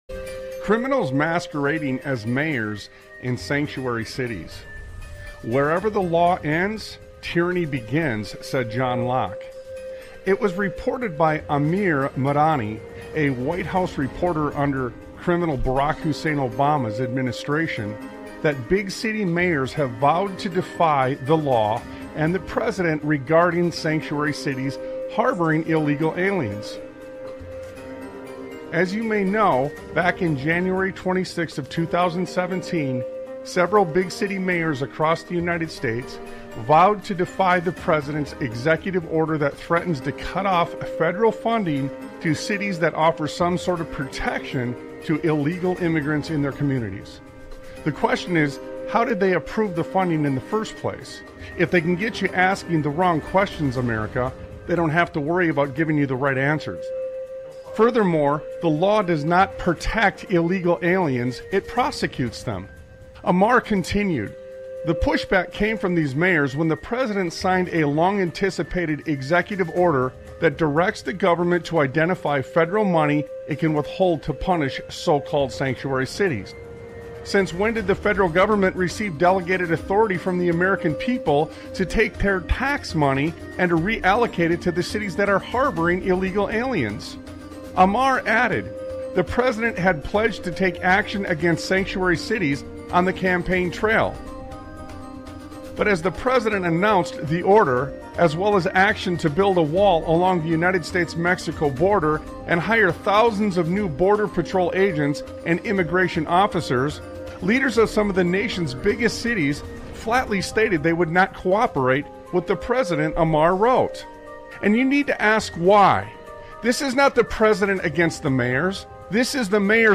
Talk Show Episode, Audio Podcast, Sons of Liberty Radio and More War Crimes Testimonies on , show guests , about More War Crimes Testimonies,The Racket of Modern Warfare,Unmasking the Military-Industrial Complex,An examination of the military-industrial complex,unconstitutional warfare,the human cost of regime change,Global Military Footprint,Testimonies of War Crimes and Moral Accountability,The Evolution of the Military-Industrial Complex,Orchestrated Conflicts, categorized as Education,History,Military,News,Politics & Government,Religion,Christianity,Society and Culture,Theory & Conspiracy